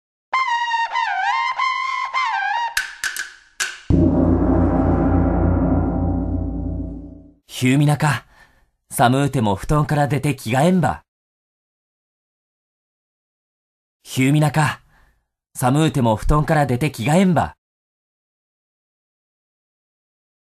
• 読み上げ